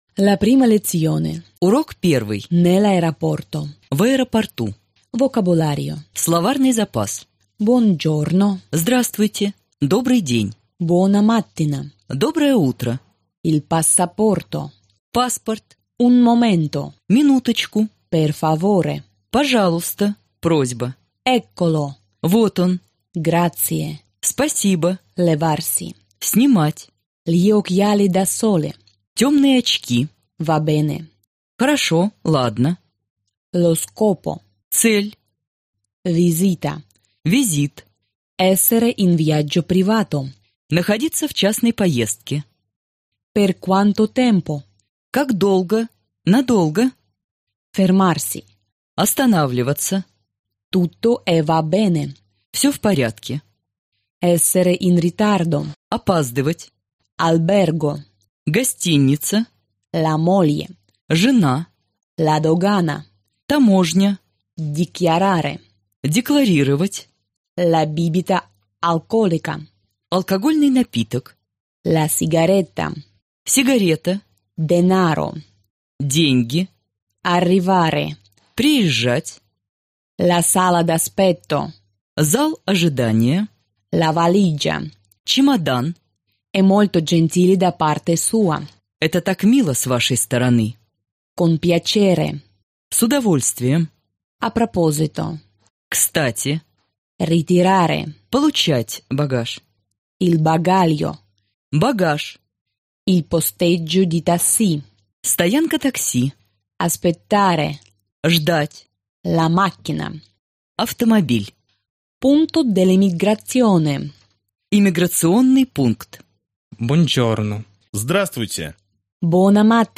Аудиокнига Итальянский язык за 2 недели | Библиотека аудиокниг
Aудиокнига Итальянский язык за 2 недели Автор Коллектив авторов Читает аудиокнигу Профессиональные дикторы.